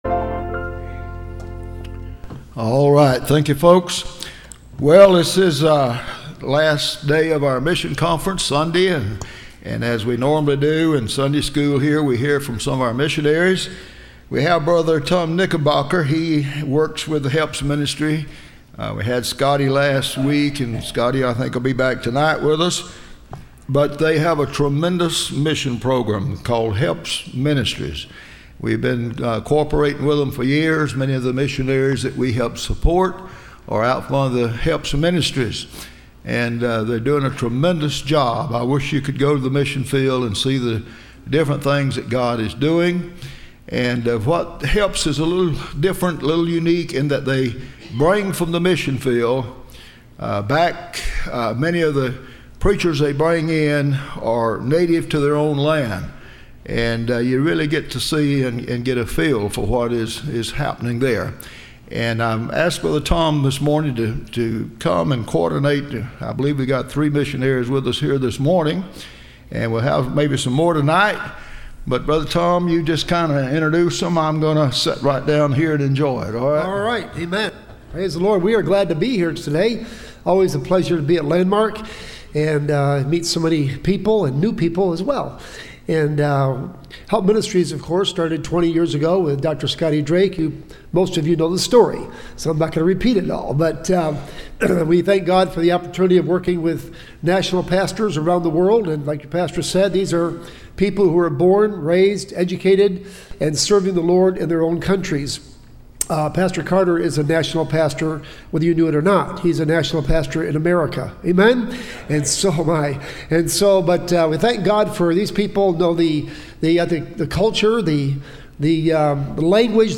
Listen to Message
Service Type: Missions Conference